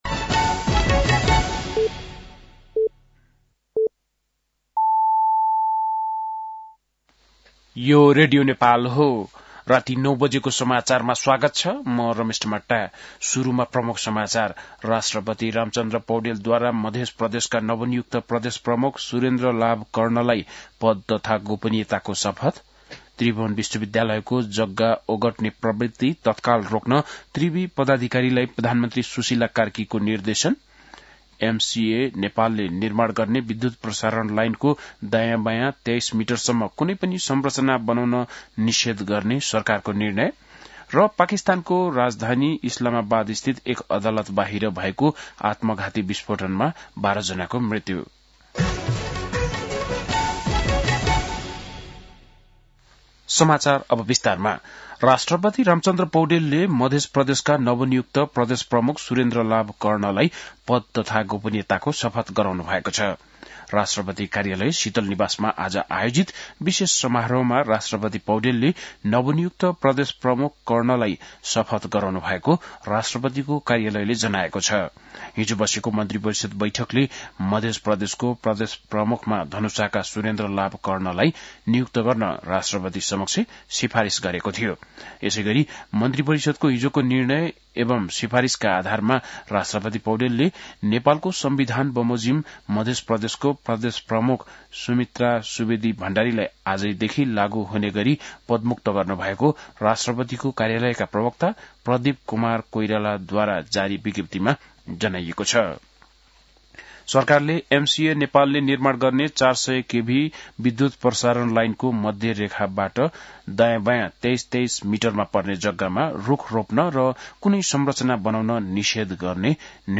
बेलुकी ९ बजेको नेपाली समाचार : २५ कार्तिक , २०८२